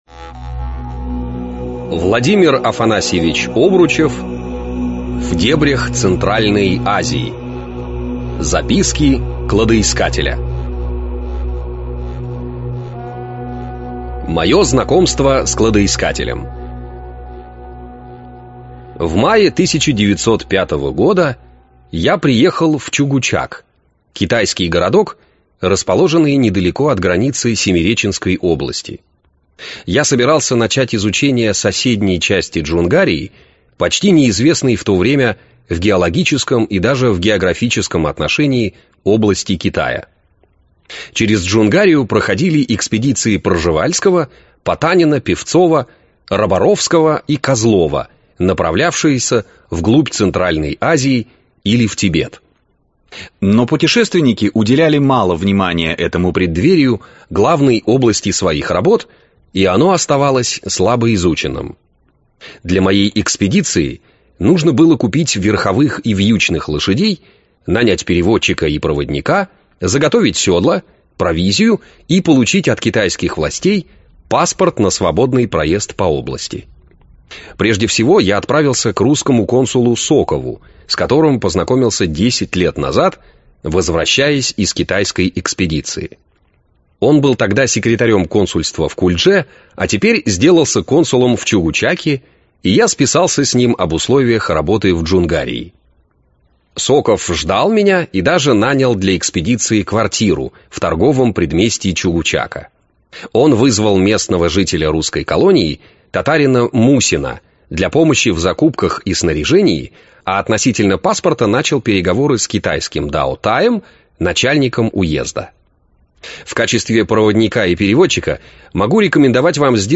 Аудиокнига В дебрях Центральной Азии. Записки кладоискателя | Библиотека аудиокниг